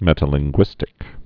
(mĕtə-lĭng-gwĭstĭk)